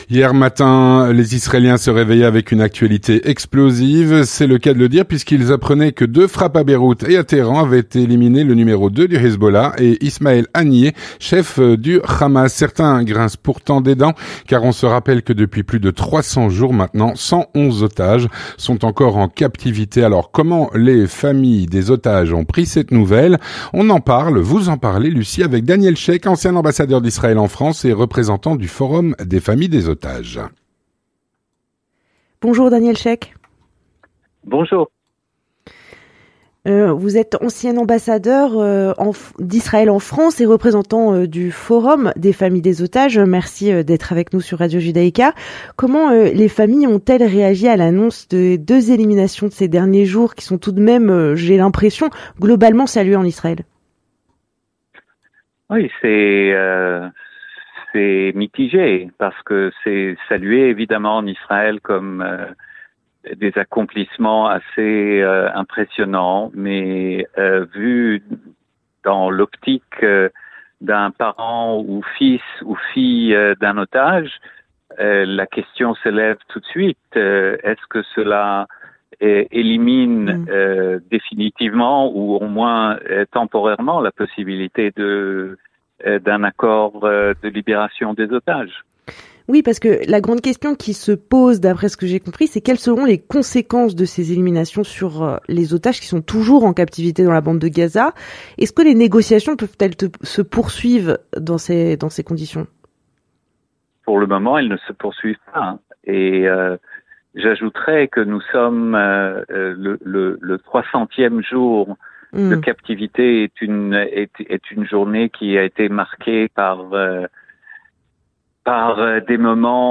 Avec Daniel Shek, ancien ambassadeur d’Israël en France, et représentant du forum des familles des otages.